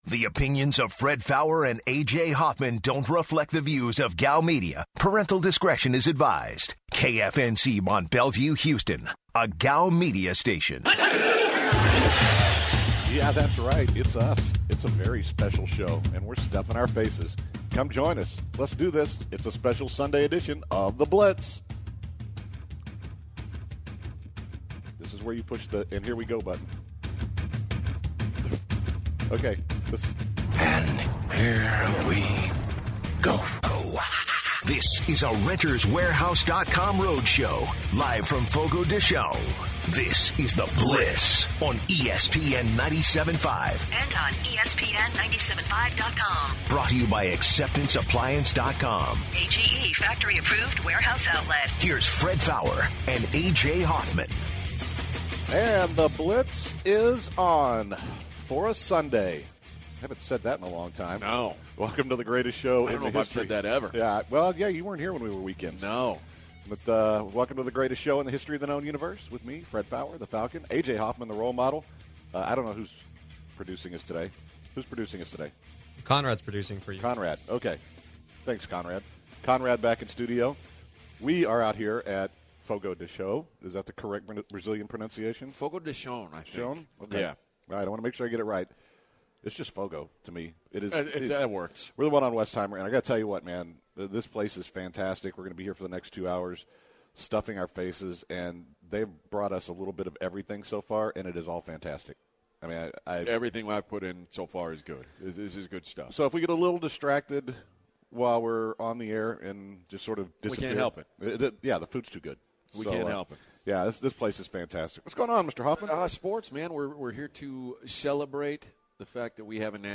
The Blitz special broadcast from Fogo De Chao.